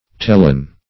tellen - definition of tellen - synonyms, pronunciation, spelling from Free Dictionary Search Result for " tellen" : The Collaborative International Dictionary of English v.0.48: Tellen \Tel"len\, n. (Zool.) Any species of Tellina.